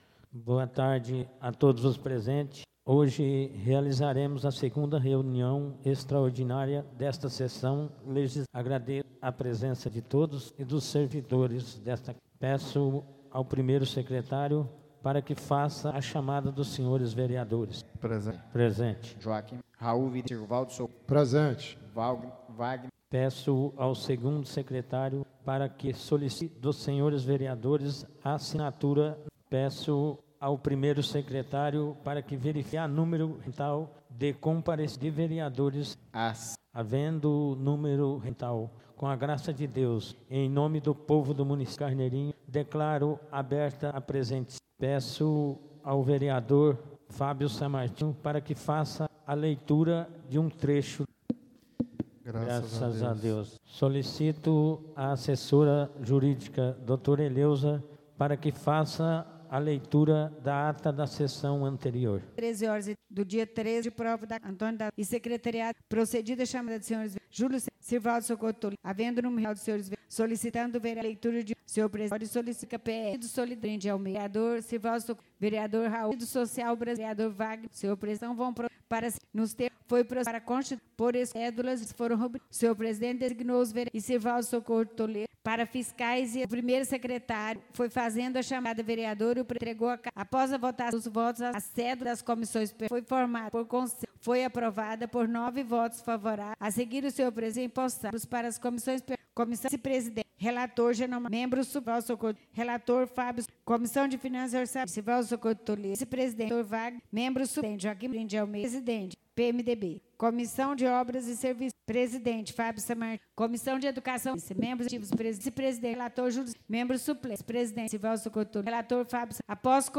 Áudio da 02ª reunião extraordinária de 2017, realizada no dia 16 de Janeiro de 2017, na sala de sessões da Câmara Municipal de Carneirinho, Estado de Minas Gerais.